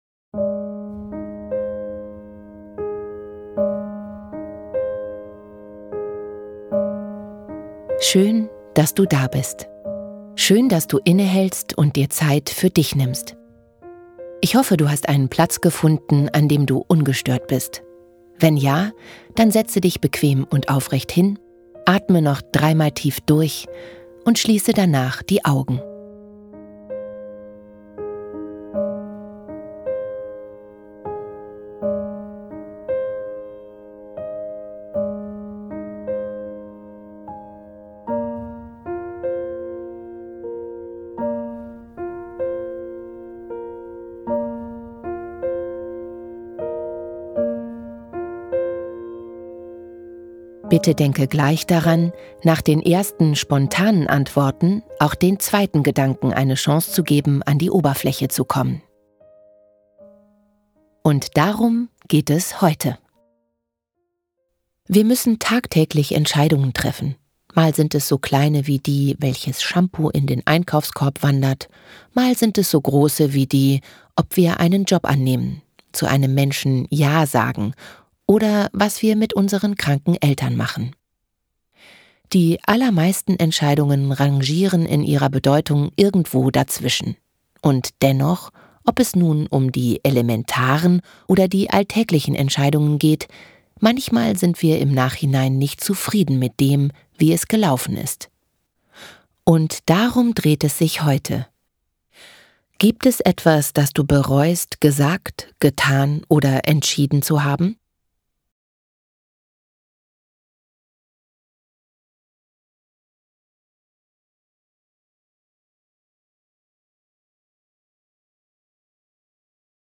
Beispiel-Reflexion